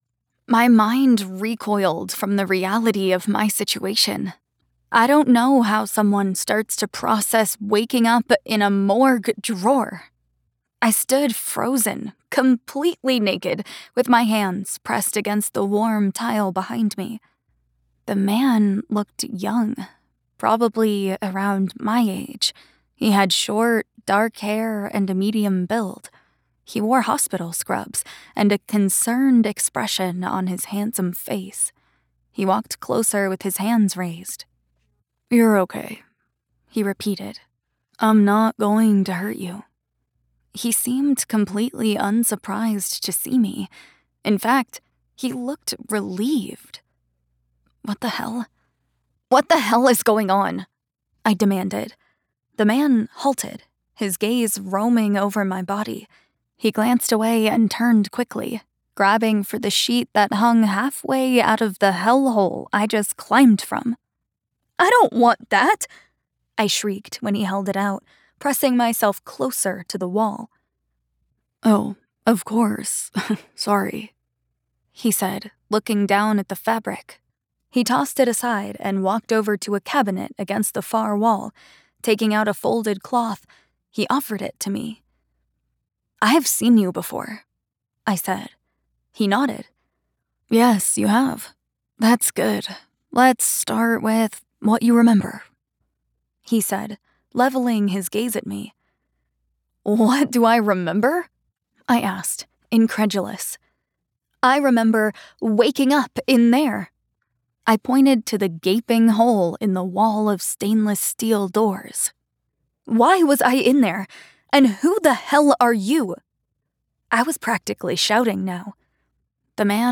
Audiobook // Fantasy 1st Person